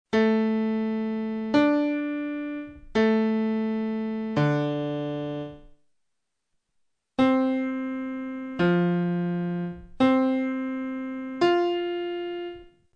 Ascoltiamo gli intervalli indicati: